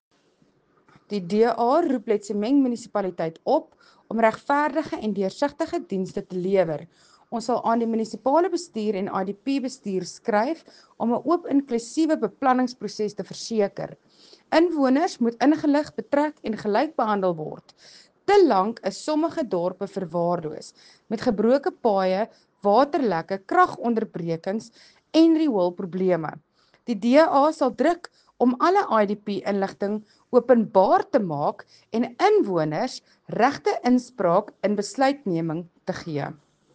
Afrikaans soundbite by Cllr Mariska Potgieter and